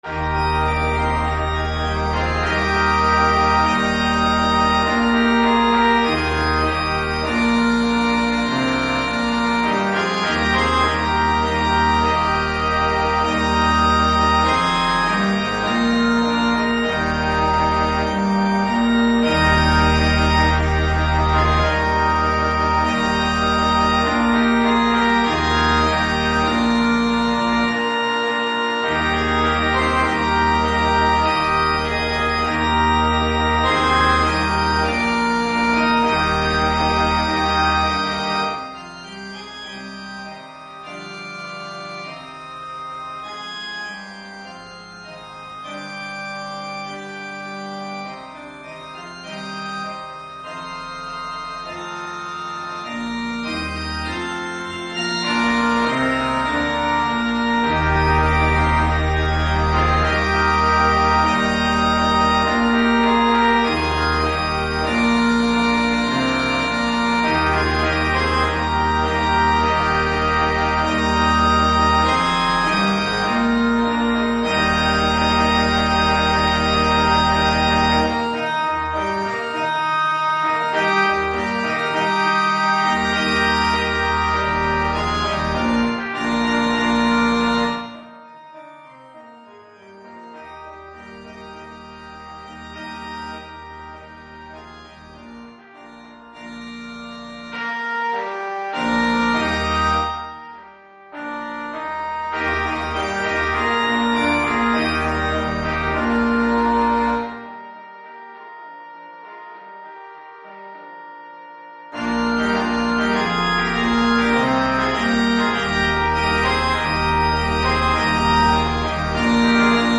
Gattung: für Blechbläserquartett
Besetzung: Ensemblemusik für 4 Blechbläser
Piano und Schlagzeug optional